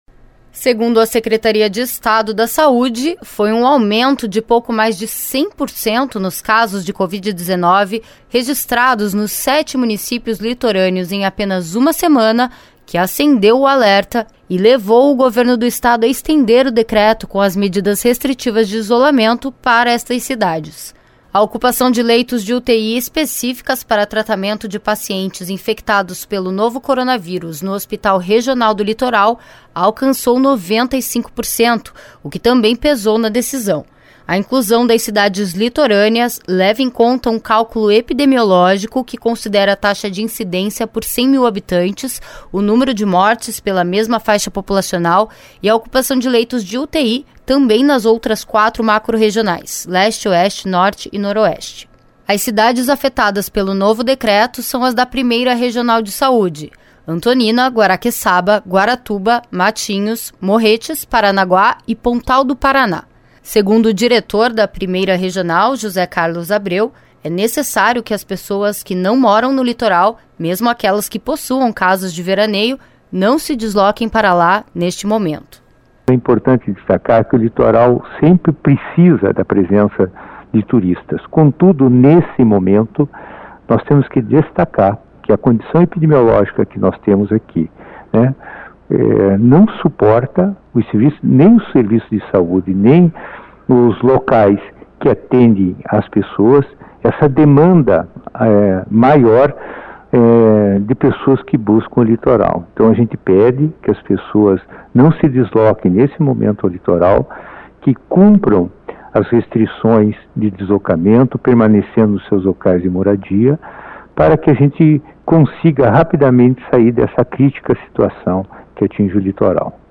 A partir desta quarta-feira passa a valer um decreto que inclui os municípios do litoral entre as cidades que estão em quarentena no estado. Além das medidas restritivas adotadas em outras cidades, barreiras sanitárias vão impedir a entrada de turistas. Os detalhes na reportagem.